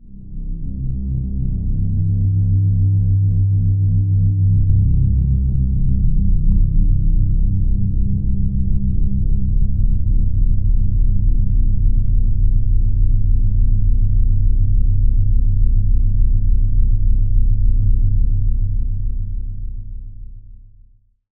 Index of /90_sSampleCDs/Club_Techno/Atmos
Atmos_13_C1.wav